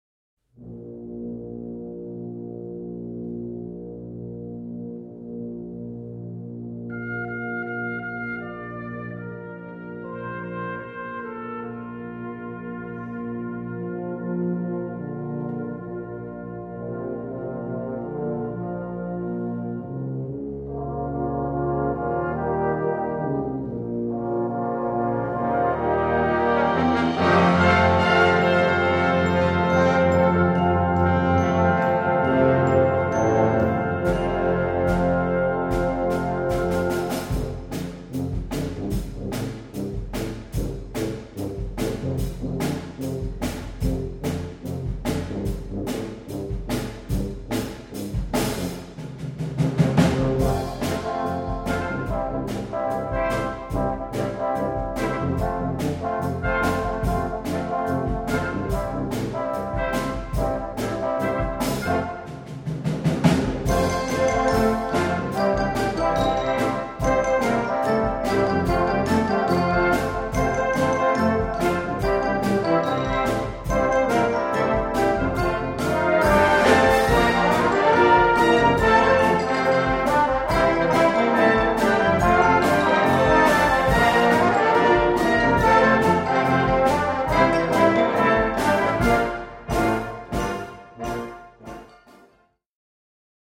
kommt neu als Rock daher.
Besetzung: Blasorchester